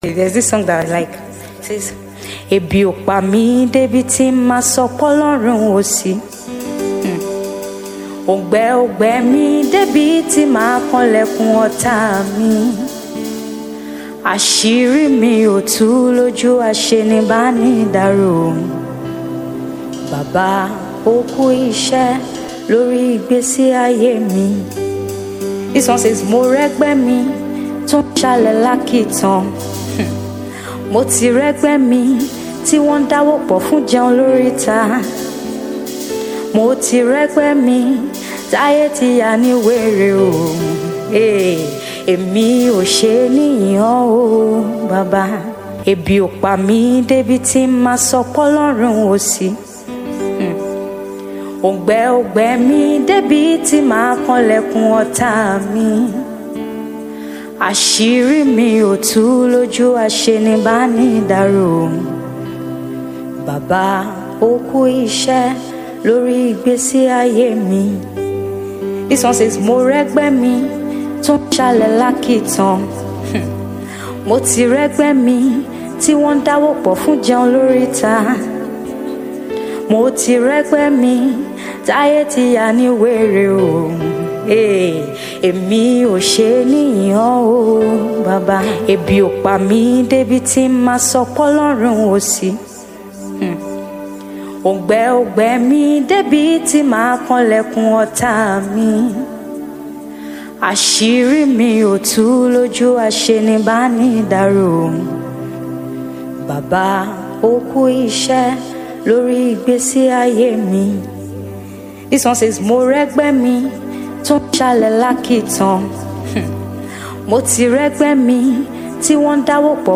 Yoruba Gospel Music